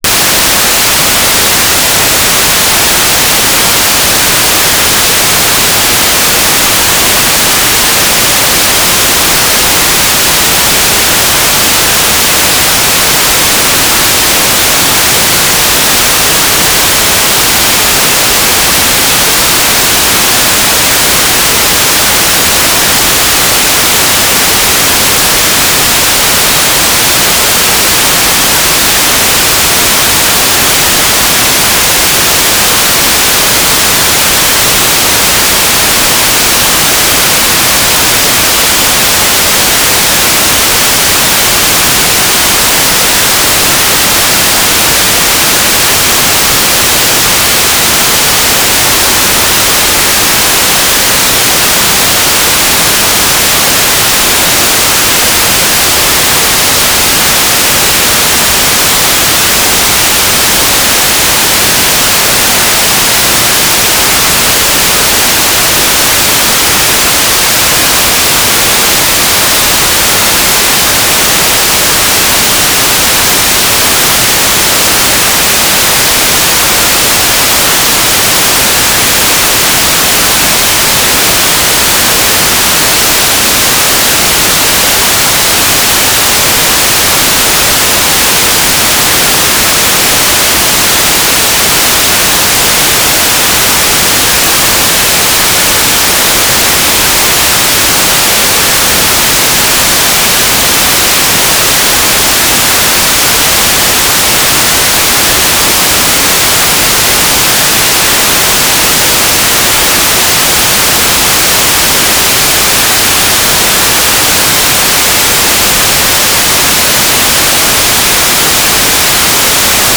"transmitter_description": "Mode U - FSK9k6 AX25",
"transmitter_mode": "FSK AX.25 G3RUH",